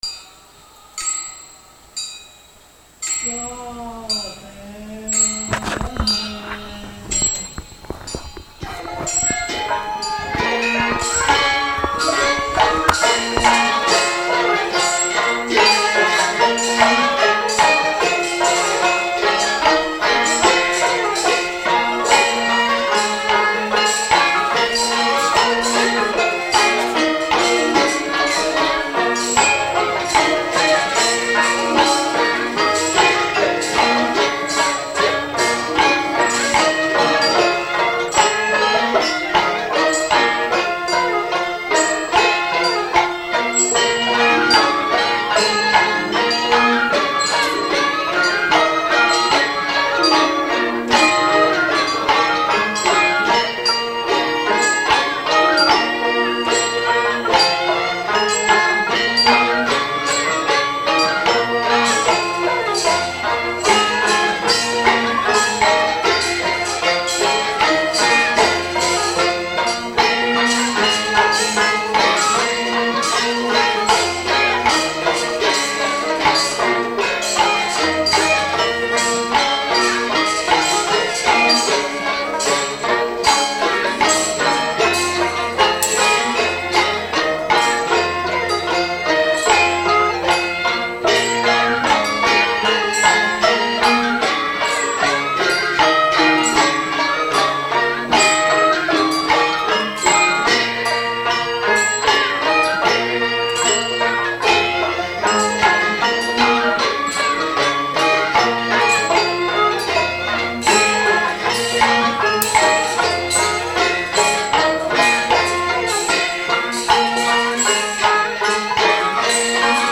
四川道教音乐—洞经古乐23
洞经古乐自明清以来即风行于中国各地文化阶层，因其主要用于谈演道教经典《玉清无极总真文昌大洞仙经》（简称洞经）而得名，集汉族民间小调、道教音乐、佛教音乐和宫廷音乐之大成，是明清时期传入丽江的道教古乐，带有汉曲丝竹乐风。